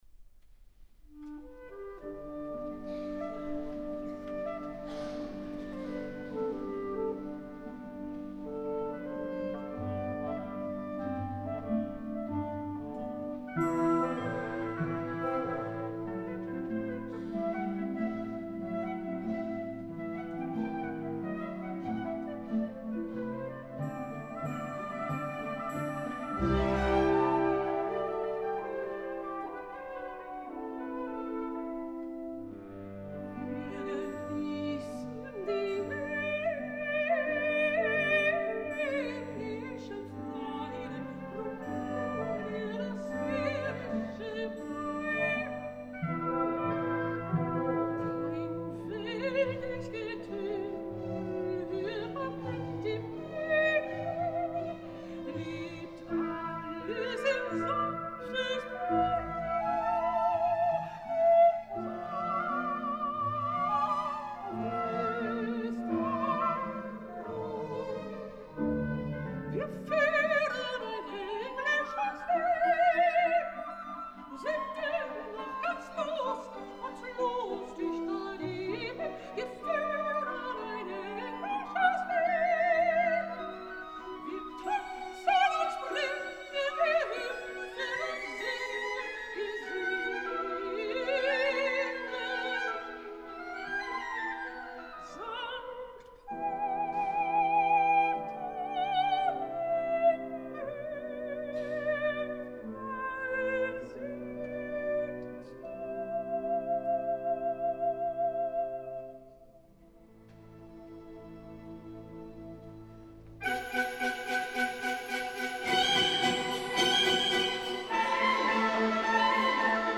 És una versió carregada de subtileses, de concepció serena i que la veu flotant de la soprano Schwanewilms ajuda a traslladar-nos a terrenys celestials sense abandonar la terra.